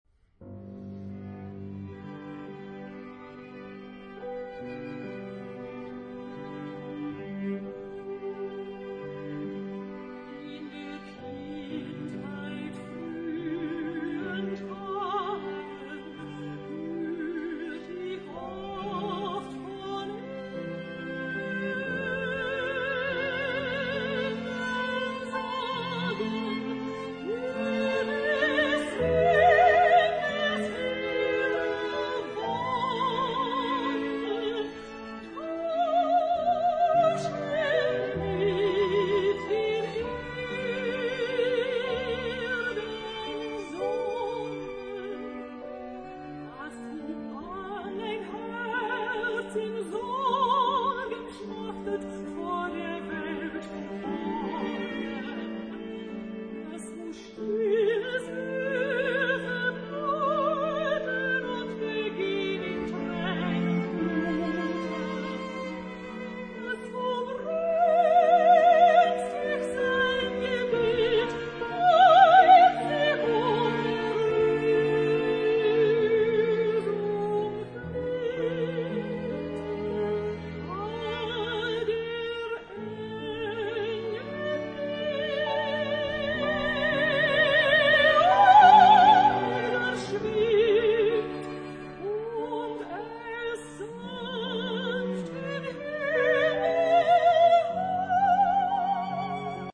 這裡則是搭配鋼琴四重奏的版本。